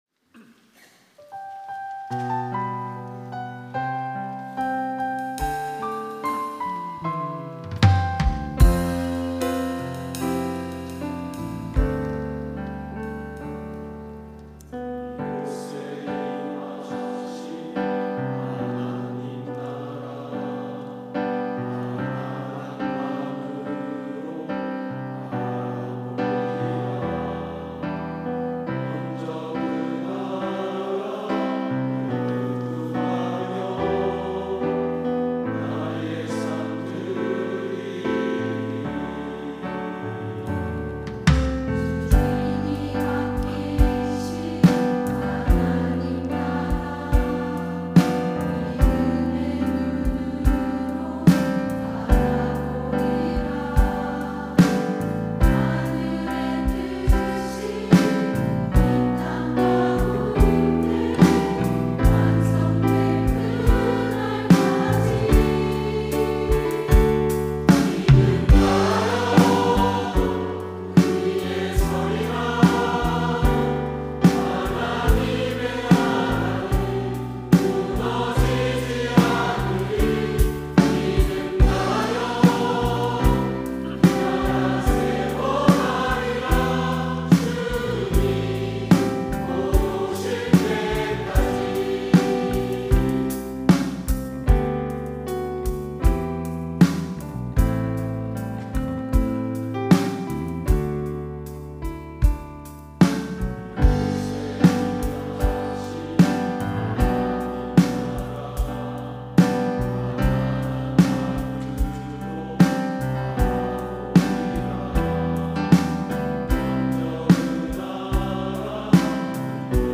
이름 교사 특송